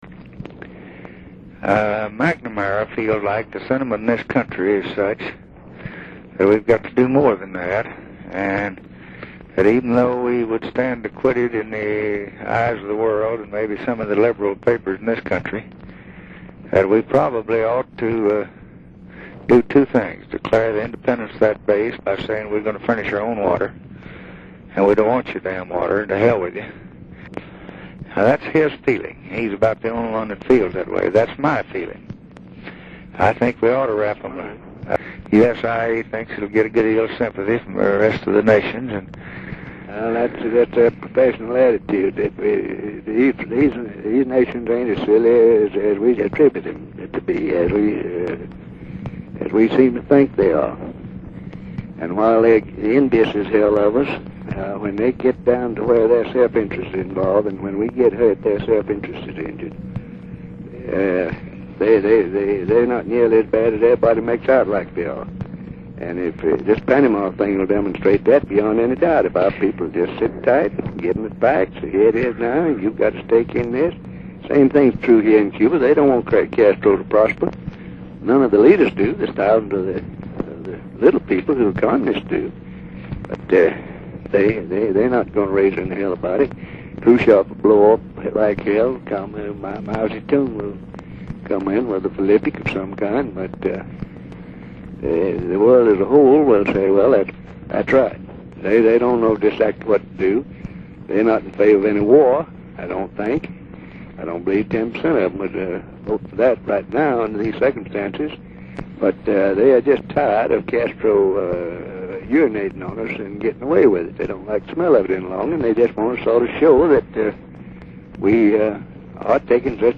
About a month later, On February 8, 1964, Johnson again turned to Russell to validate a hard-line response to a minor foreign policy problem, in this instance the arrest of Cuban fishermen off the Florida coast—which prompted Castro to cut off the water to the U.S. base in Guantanamo and led the United States (ultimately) to fire all Cuban workers on the base.